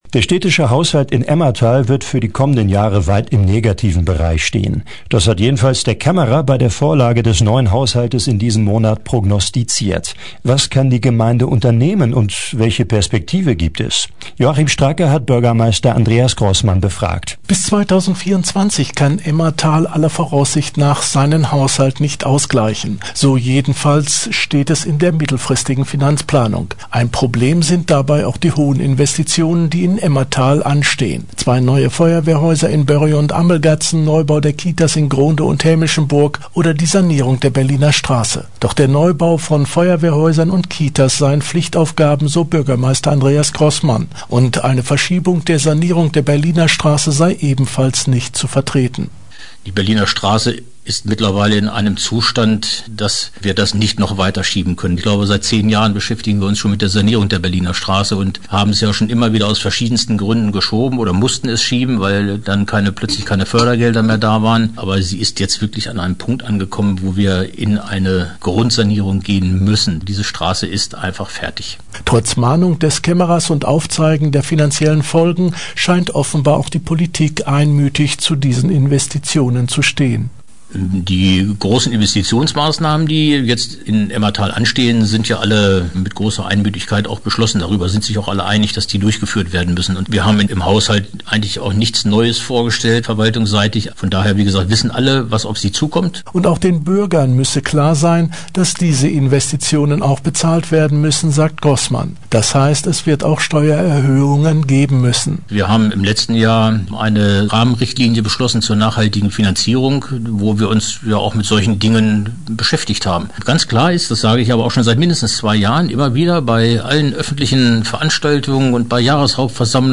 Aktuelle Lokalbeiträge Emmerthal: GROSSMANN EMMERTHAL Play Episode Pause Episode Mute/Unmute Episode Rewind 10 Seconds 1x Fast Forward 30 seconds 00:00 / Download file | Play in new window Der städtische Haushalt in Emmerthal wird für die kommenden Jahre weit im negativen Bereich stehen: Bürgermeister Andreas Großmann zur finanziellen Entwicklung in Emmerthal und den Perspektiven, die sich daraus ergeben…